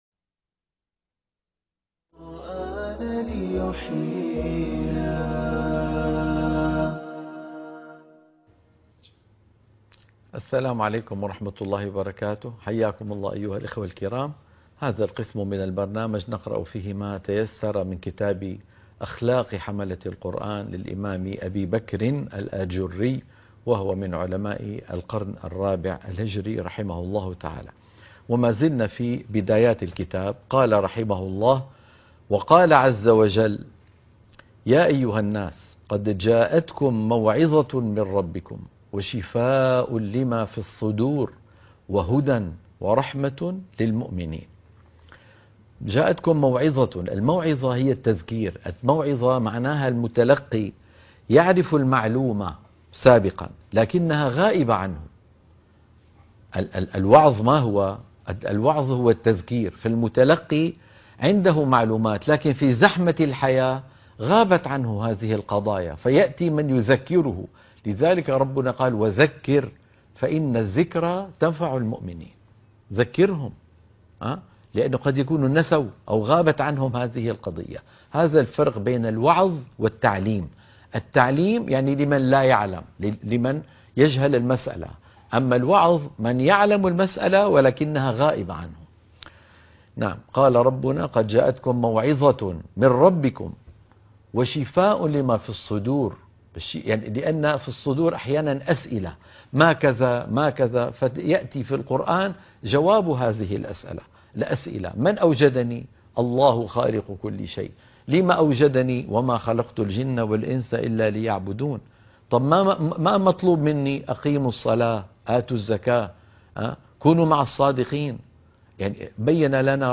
قراءة كتاب أخلاق حملة القرآن _ الحلقة الثالثة - الشيخ أيمن سويد